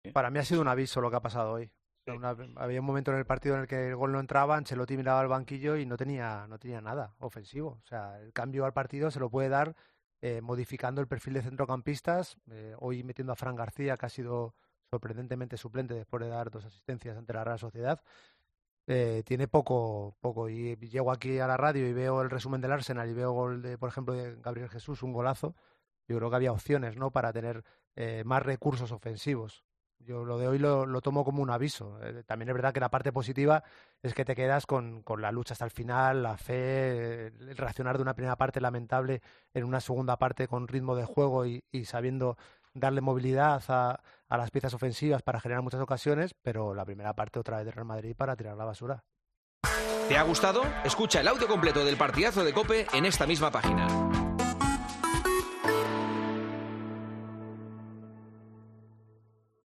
Escucha las palabras completas de los tertulianos de El Partidazo de COPE sobre el partido del Real Madrid